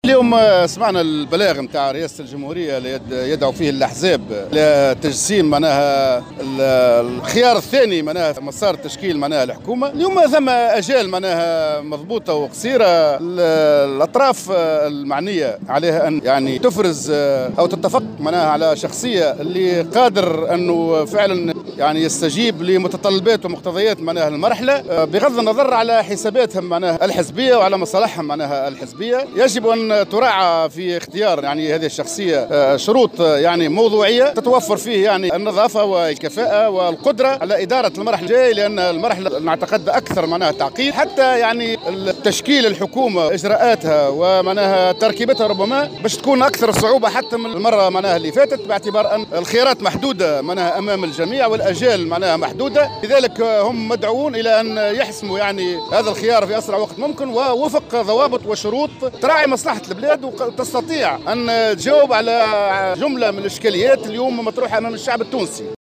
قال القيادي في الجبهة الشعبية زهير حمدي في تصريح لمراسل الجوهرة أف أم اليوم...